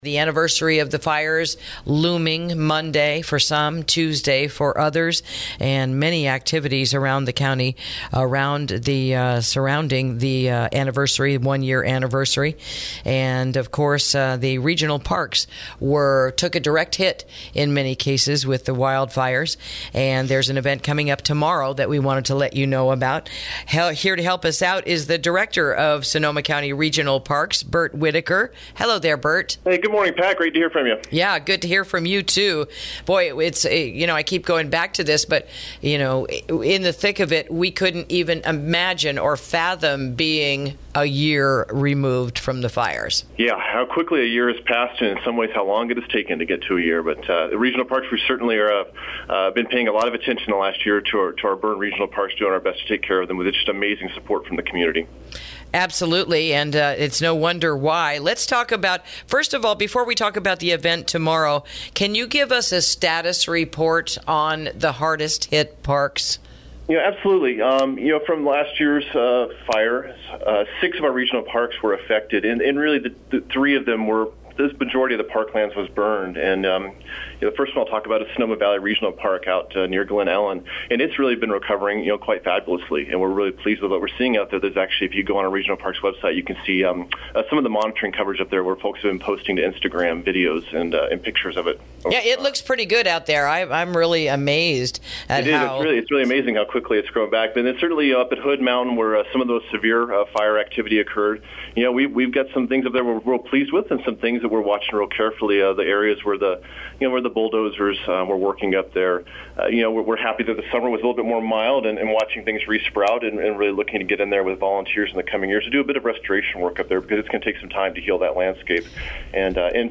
INTERVIEW: Shiloh Ranch Regional Park Wildfire Anniversary Event This Saturday